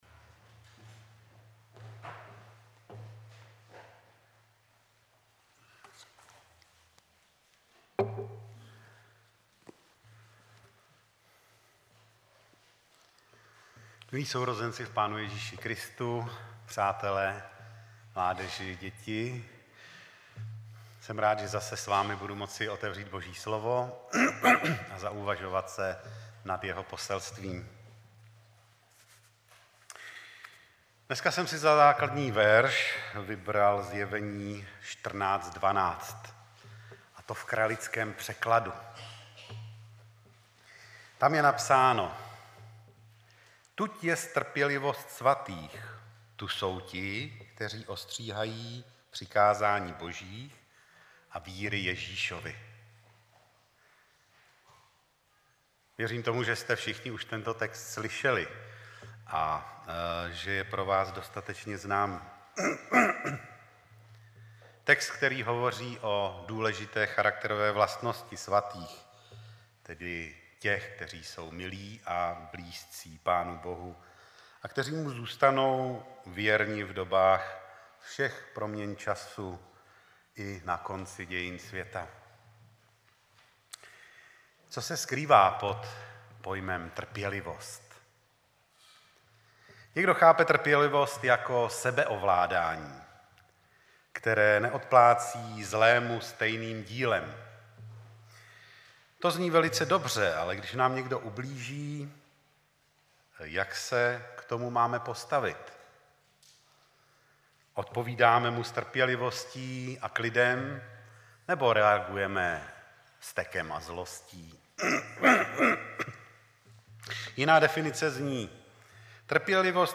25.9.2016 v 10:38 do rubriky Kázání .